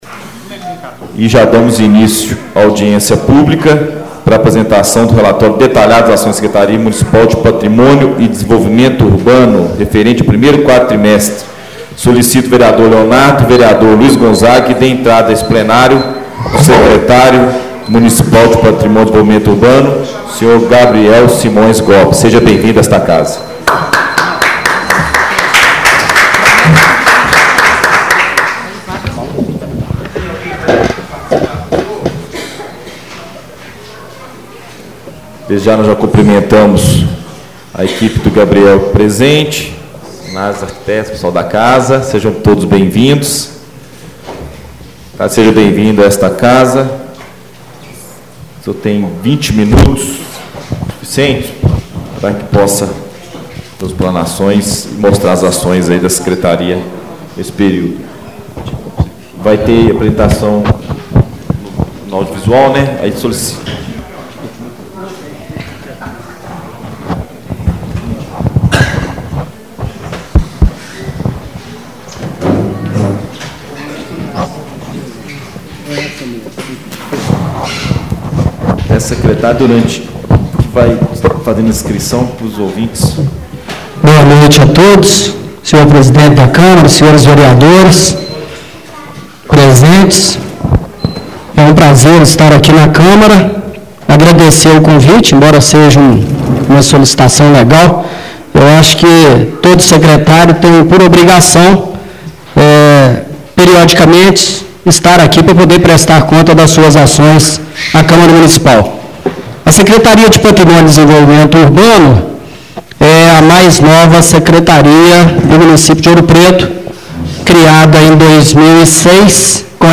Áudio: | Câmara Municipal de Ouro Preto Audiência Pública da Câmara Muninicpal: Apresentação do relatório detalhado das ações da Secretaria Municipal de Patrimônio e Desenvolvimento Urbano?